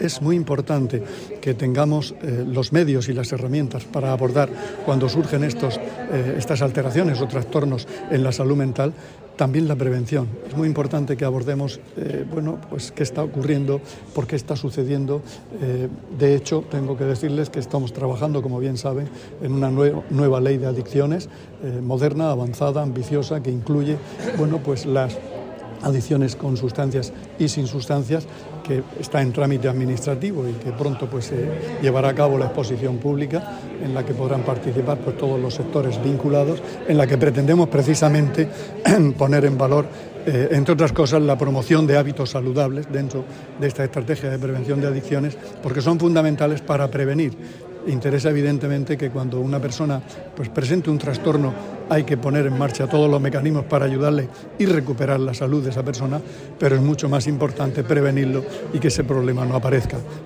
Declaraciones del consejero de Salud, Juan José Pedreño, en la inauguración del II Congreso de Salud Mental Fundación Atalaya 'Salud mental de la Juventud en la Región de Murcia'